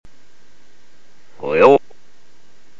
… and here is that same recording played backwards …
Incidentally, I recorded & saved both versions using the built-in Windows accessory called “Sound Recorder” and I converted the resulting WAV files to mp3 with a program called CDex.